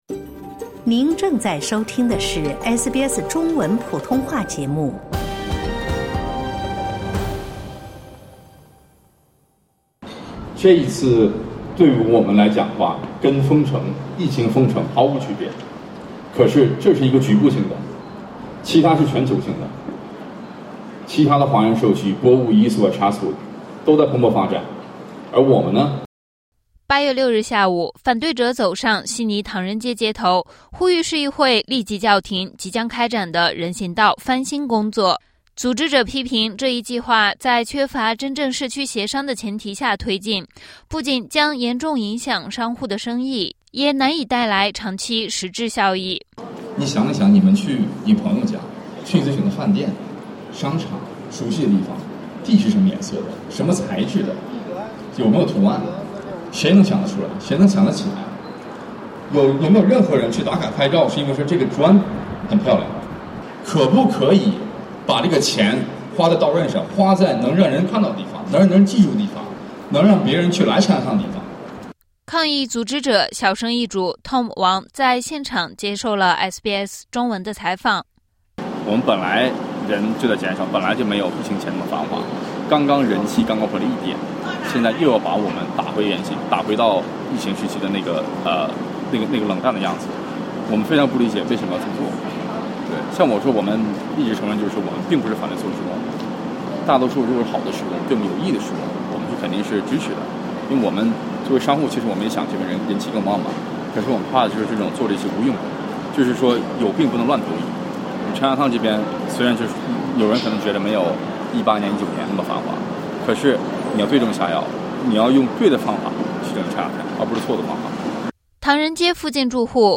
悉尼唐人街信德街（Dixon St）的地面翻新工程将于8月底开工，部分华人商户强烈反对，批评此举没有实质意义，且会扰乱唐人街的好不容易正在恢复的人流量。市议会回应称，施工将分阶段、小范围进行，并为部分商户提供翻新补贴。（点击音频收听报道）